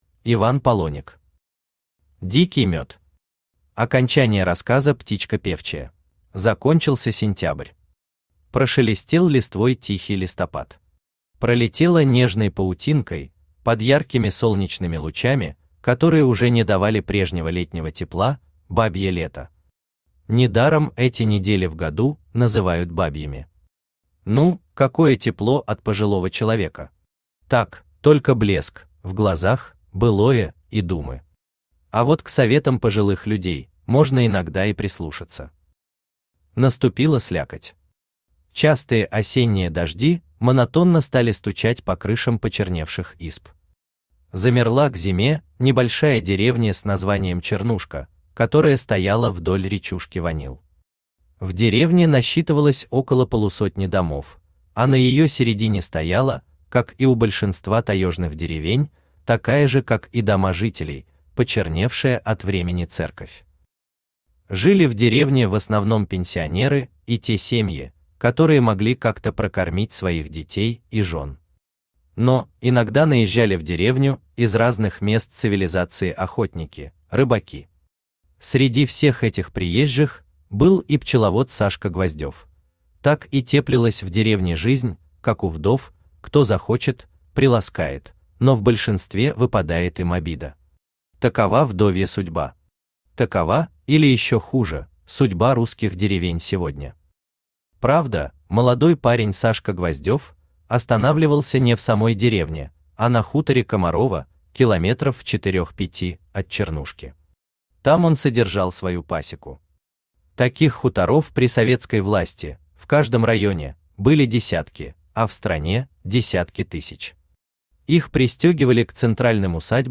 Aудиокнига Дикий мед Автор Иван Полоник Читает аудиокнигу Авточтец ЛитРес.